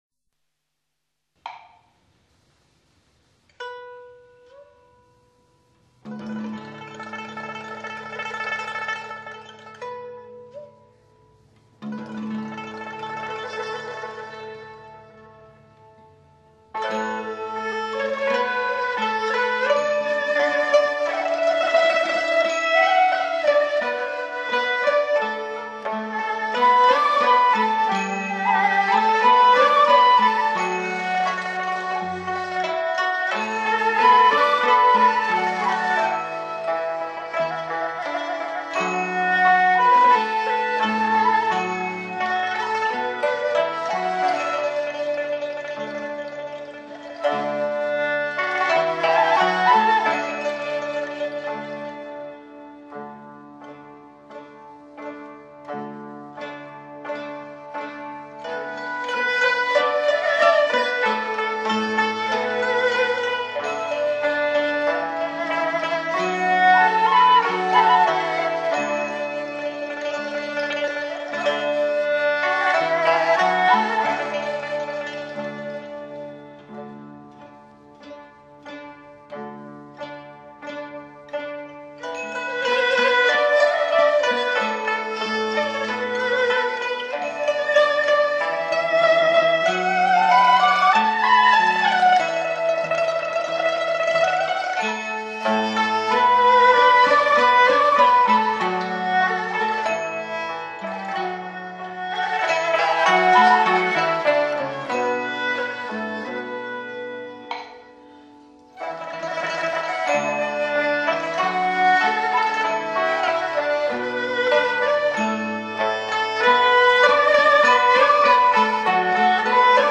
本碟收录了我国优秀的民族乐曲，淡而清雅，可作为肘边首选的时尚休闲音乐！